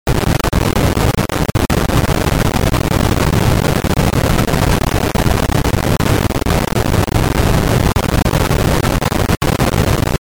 Mysterious Static on all Record Takes
The microphone I'm currently recording with as an akg perception 200. Here's the issue: static on every record take.
The vocal is clear but there's a static thats tough to remove with waves noise plug ins.